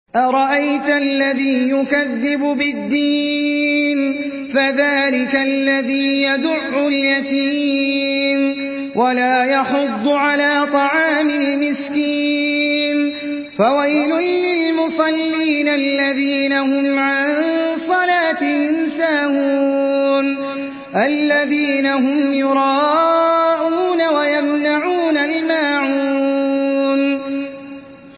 Surah আল-মা‘ঊন MP3 by Ahmed Al Ajmi in Hafs An Asim narration.
Murattal Hafs An Asim